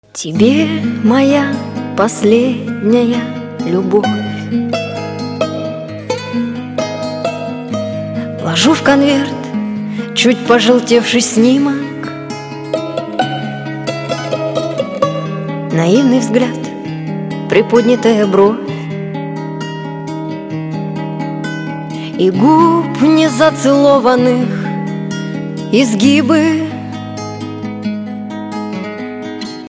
Категория: Спокойные